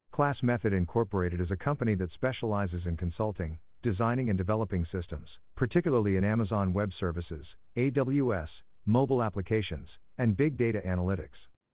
Amazon Polly (TTS)
Amazon Polly は明確で聞き取りやすい音声ですね。
tts_en.wav